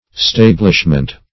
Stablishment \Stab"lish*ment\ (-ment), n.
stablishment.mp3